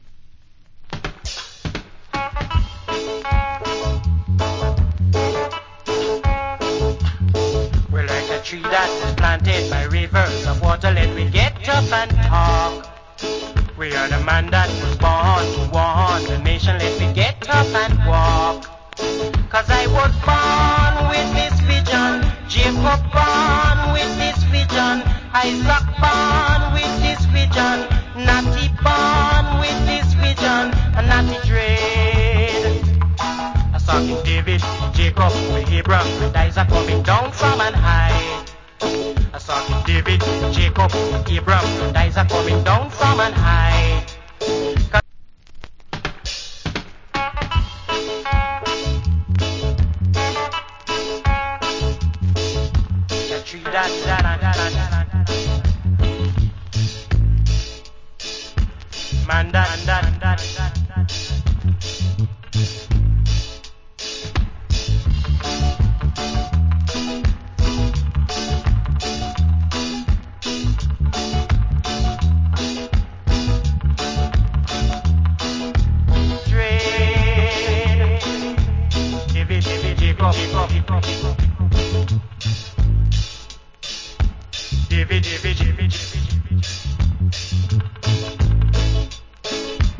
Cool Roots Rock Vocal. Rec At Black Ark.